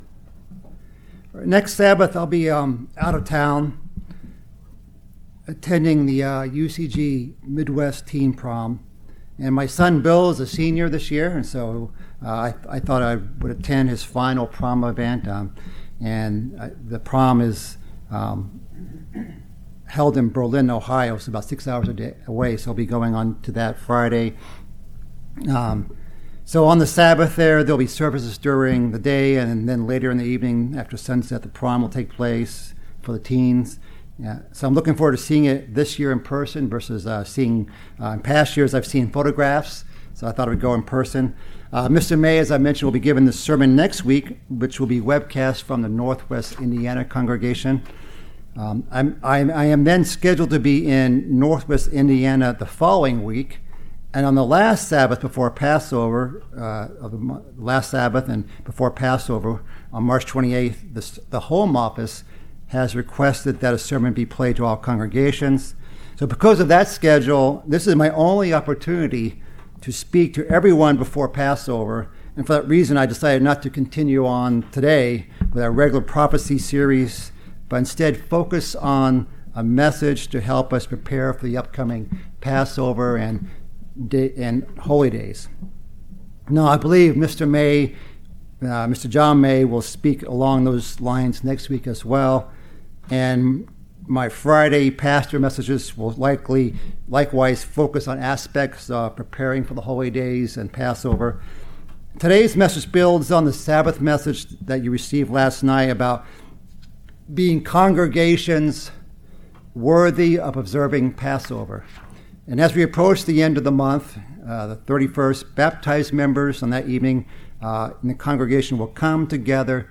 Sermons
Given in Chicago, IL Beloit, WI Northwest Indiana